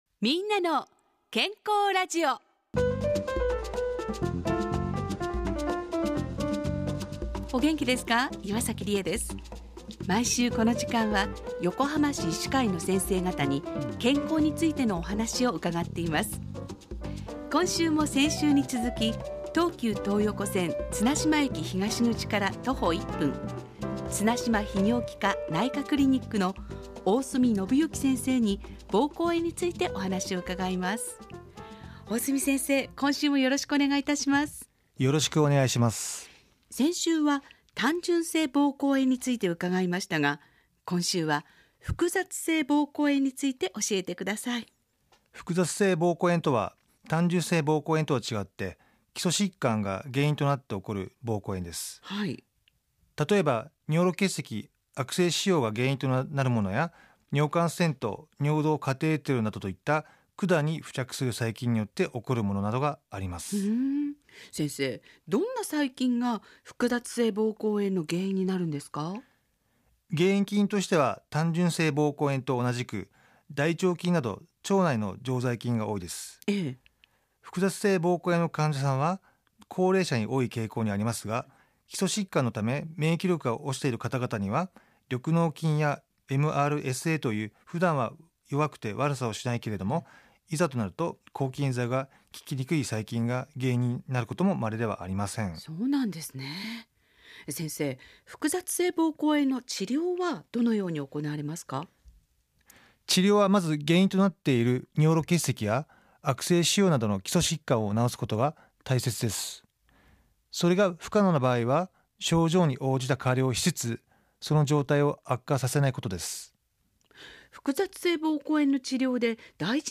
ラジオ番組 みんなの健康ラジオ